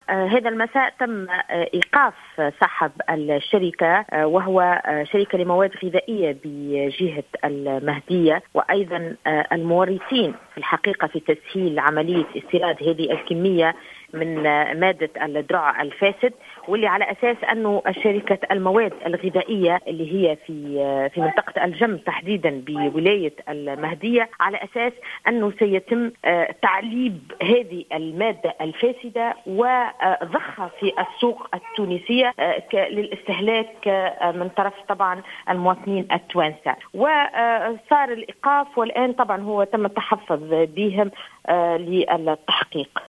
أكدت النائب بمجلس نواب الشعب حياة عمري،في تصريح للجوهرة "اف ام" أنه تم مساء اليوم ايقاف صاحب الشركة الموردة لمادة الدرع الفاسد وعدد من المتورطين معه.